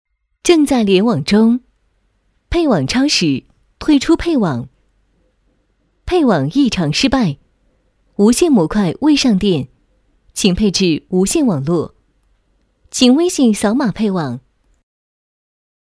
云喇叭 - 女3号 梦梦 - 双讯乐音旗舰店